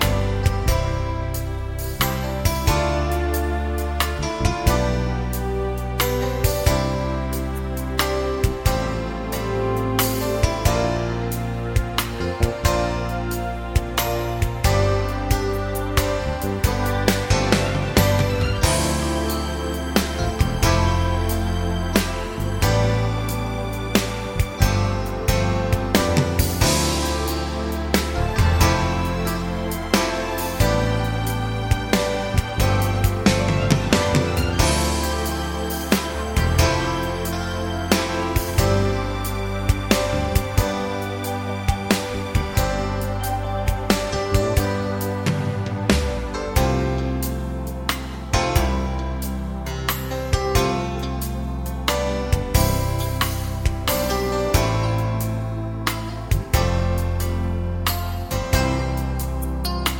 no Backing Vocals or sax Soft Rock 4:24 Buy £1.50